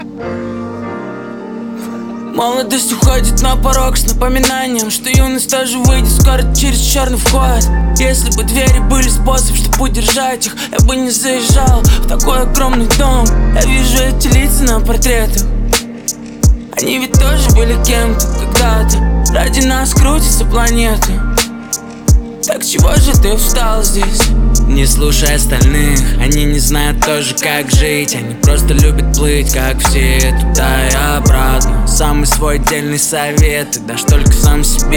# Хип-хоп